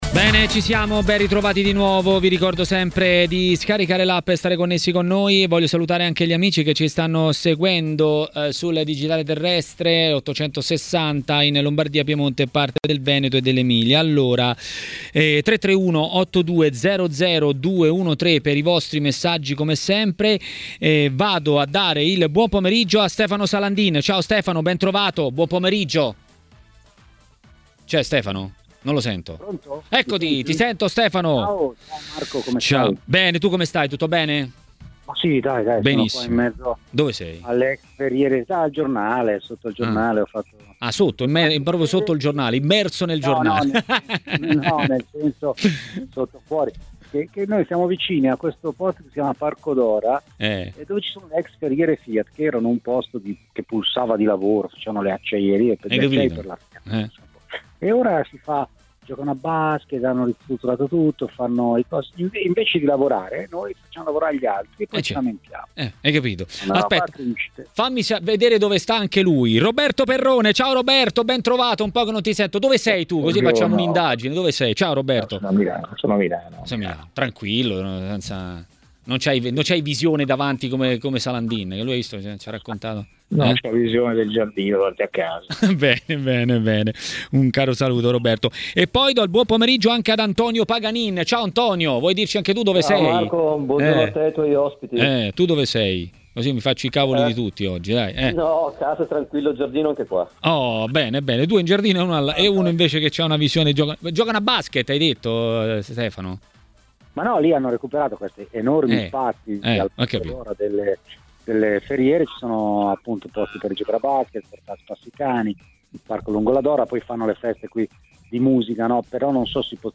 Ai microfoni di 'Maracanà', trasmissione di Tmw Radio, è stato l'ex calciatore Antonio Paganin.